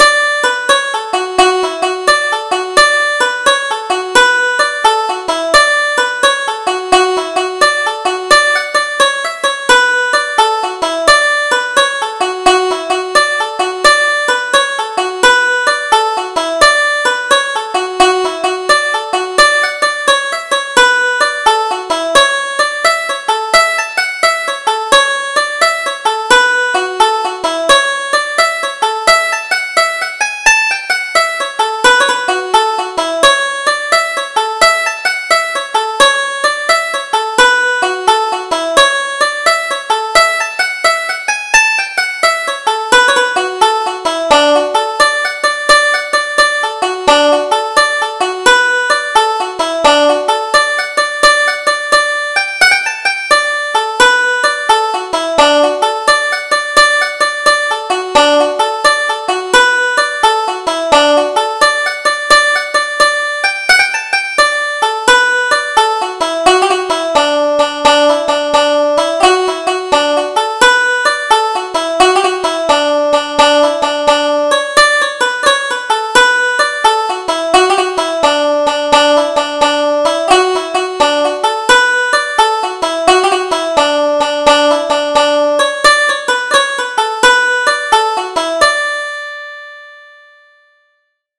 Double Jig: Wallop the Spot